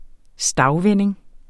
Udtale [ ˈsdɑwˌvεneŋ ]